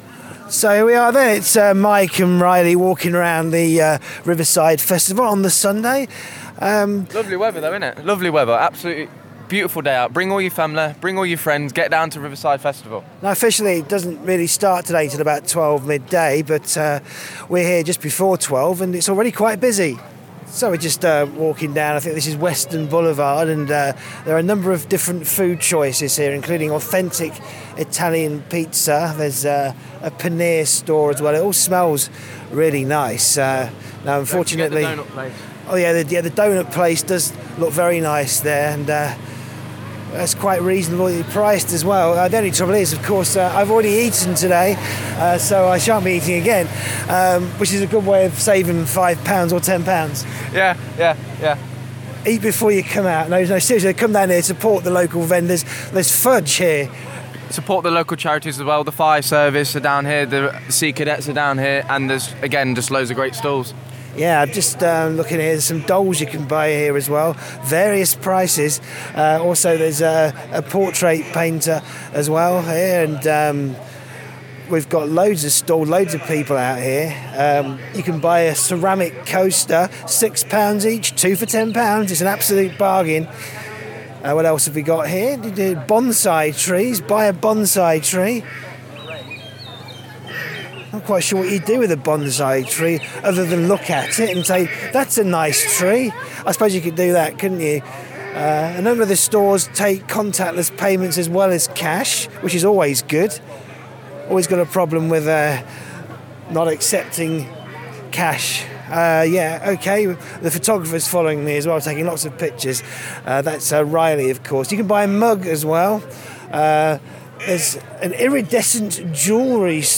Soar Sound was there once again, broadcasting live from the De Montfort University campus and talking with the people who make this event such a unique reflection of Leicester’s diverse communities.
Our microphones roamed through Bede Park and along Western Boulevard, where stallholders and charity groups shared insights into their work, their passions, and why being part of this year’s festival matters. From community health projects and environmental campaigns to craft makers and local traders, we captured the voices of people who are working to make a difference in Leicester every day.